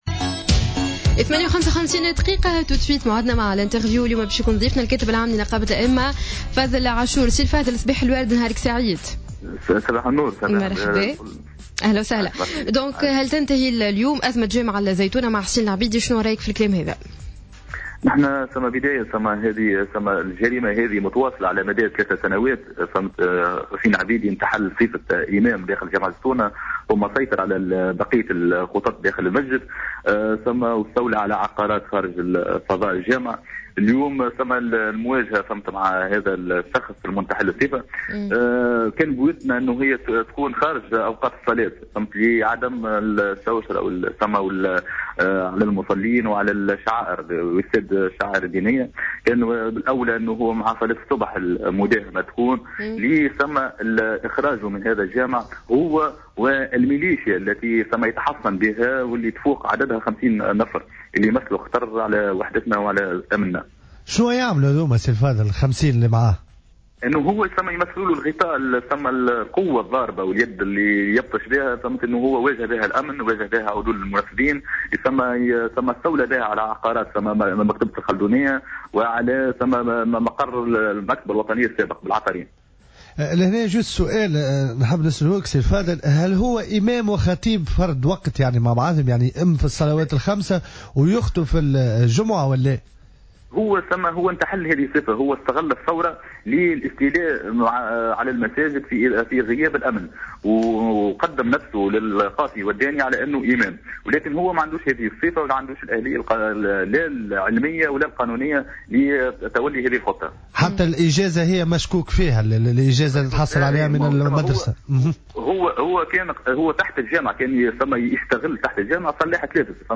في مداخلة له على جوهرة "اف ام" صباح اليوم الجمعة